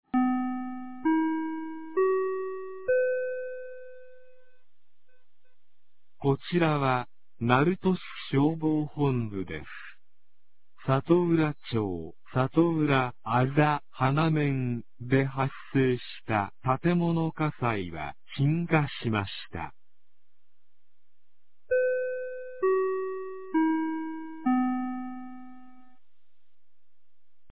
2025年11月16日 02時24分に、鳴門市より全地区へ放送がありました。